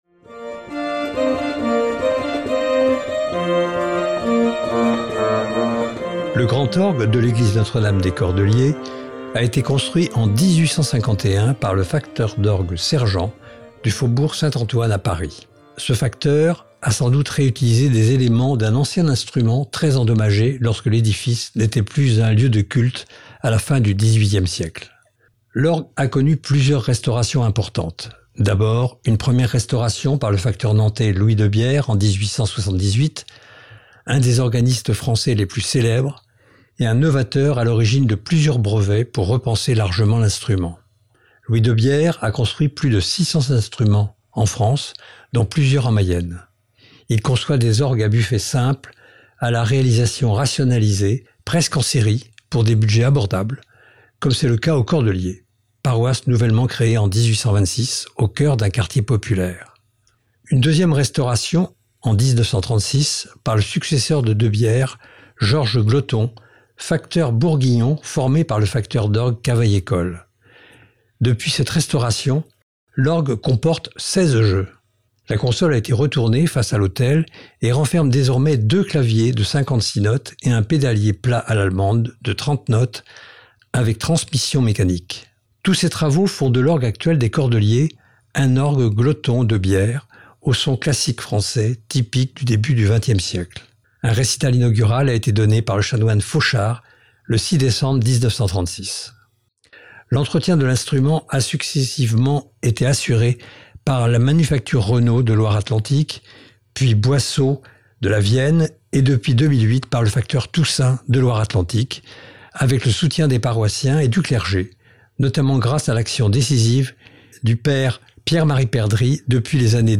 L'Orgue de l'église des Cordeliers
Tous ces travaux font de l’orgue actuel des Cordeliers un orgue Gloton-Depierre, au son classique français, typique du début du XXe siècle Un récital inaugural a été donné par le chanoine Fauchard le 6 décembre 1936.
Cliquez sur « en savoir plus » pour entendre l’orgue dans le « Duo de trompettes » de Louis-Nicolas Clérambault (1676-1749). Enregistrement réalisé sur l’orgue Gloton-Debierre de Notre-Dame des Cordeliers le dimanche 30 octobre 2022
18-Orgue_CORDELIERS_mixage-final.mp3